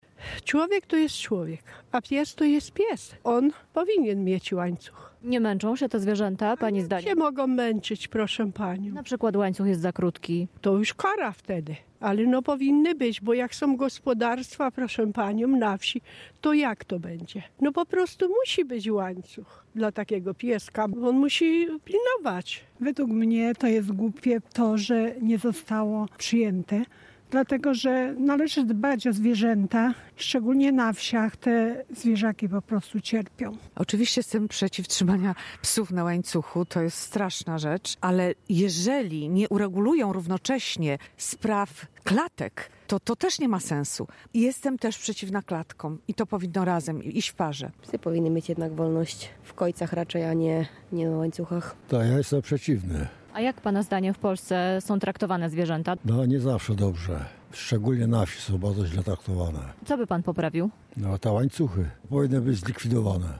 Sprawdziliśmy co na temat trzymania psów na łańcuchach myślą mieszkańcy Rzeszowa: